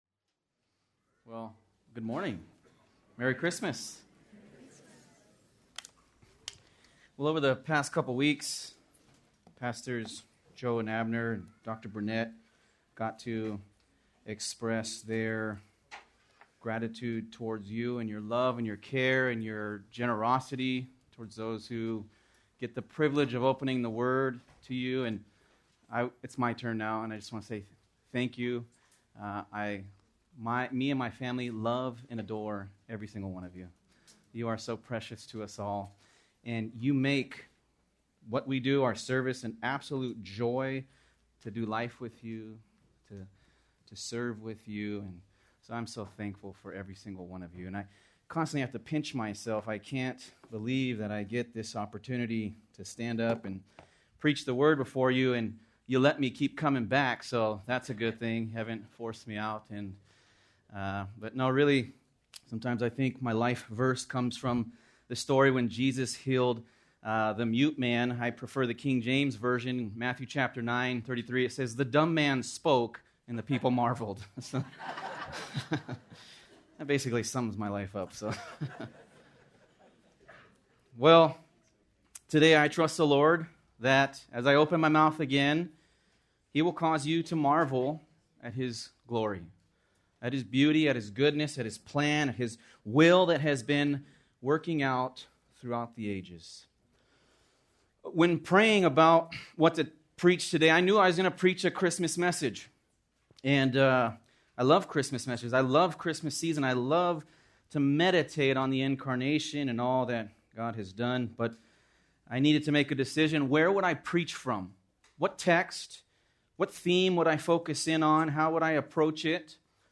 December 21, 2025 - Sermon | Sojourners | Grace Community Church